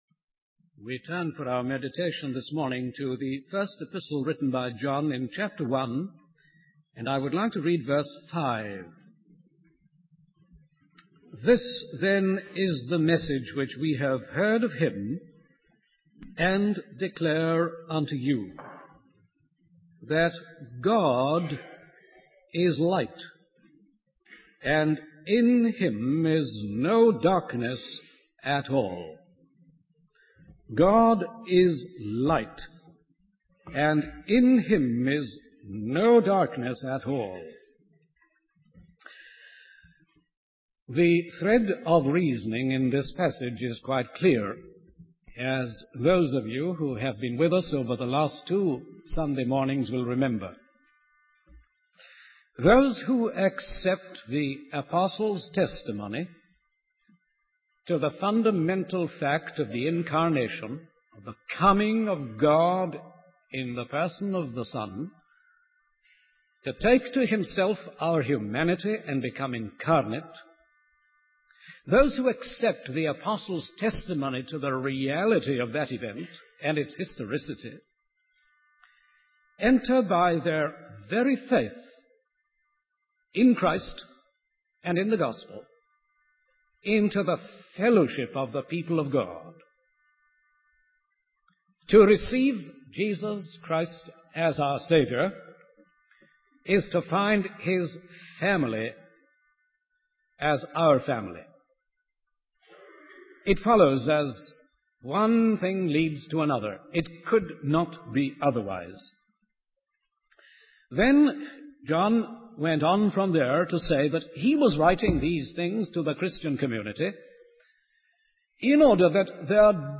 In this sermon, the preacher focuses on the message found in 1 John 1:5, which states that God is light and there is no darkness in Him. The sermon emphasizes the importance of accepting the testimony of the Apostles regarding the incarnation of God in Jesus Christ.